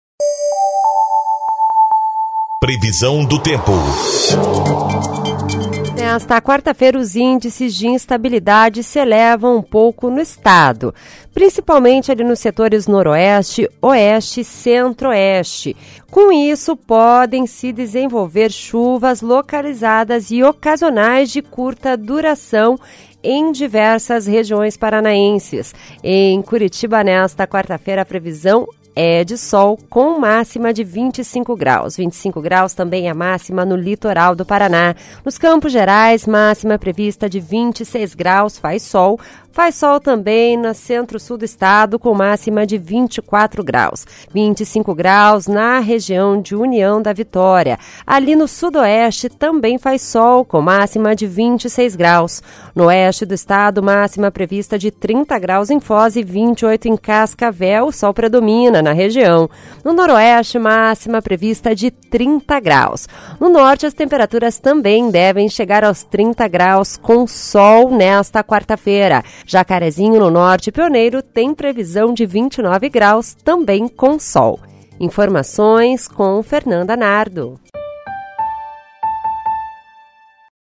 Previsão do Tempo (12/04)